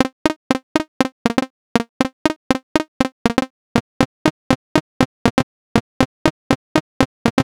I selected the 1 voice template and lowered the decay time on both synths. I set both synths to play the same pattern and the latency was easily audible.
I did the same with iPolysix, and though there is some latency there too, it is noticeable shorter.
In the attached file first is iMono/Poly and then iPolysix.